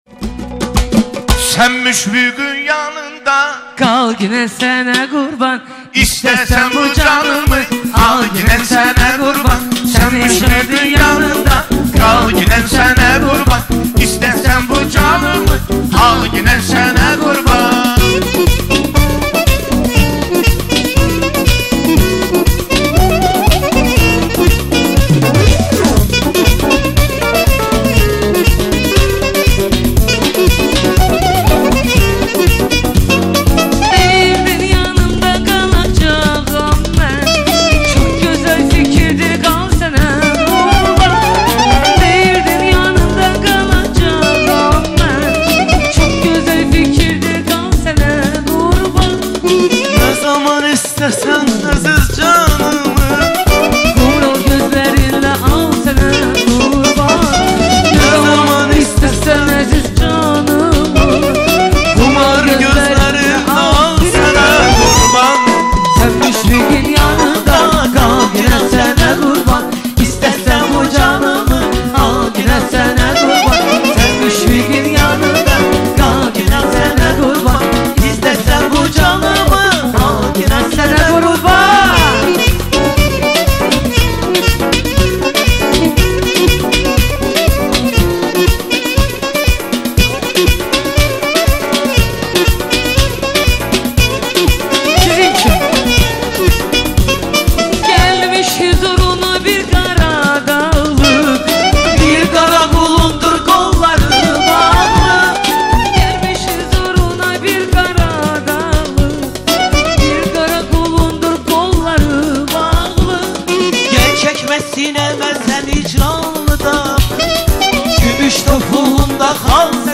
Canlı ifa